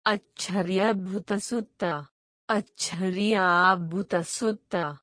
The apostrophe stresses the second a which is a slightly different vowel: əcchəɾɪjə’abbʰʊt̪əsʊt̪t̪ə